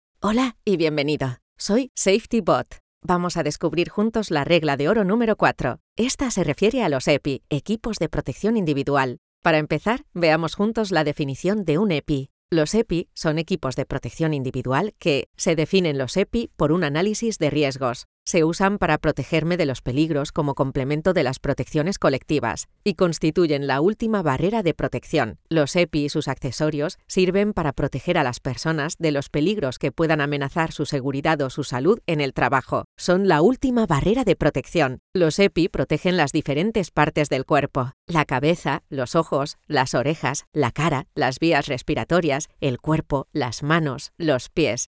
Naturelle, Polyvalente, Profonde, Accessible, Chaude
E-learning